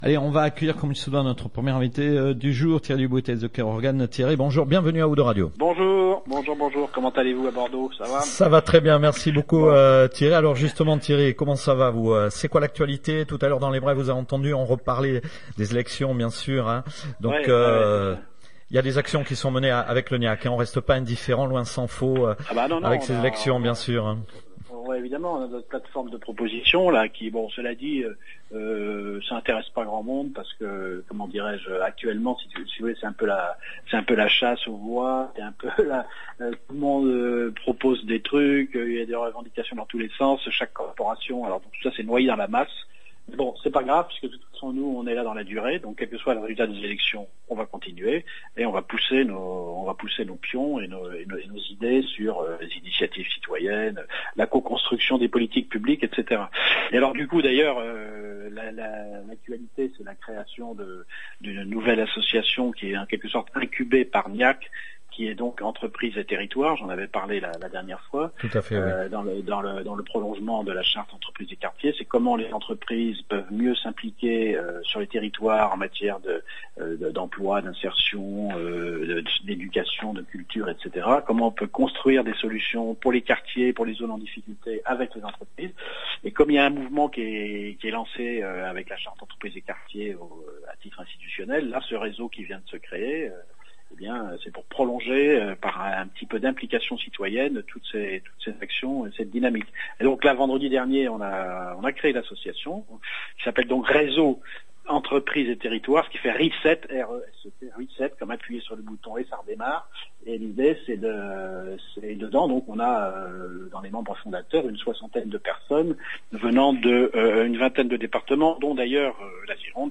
(insertion professionnelle - "être prêts à travailler"), avec un gros travail de coopérations avec différentes associations et collectivités. INTERVIEW